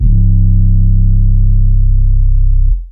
808 (War).wav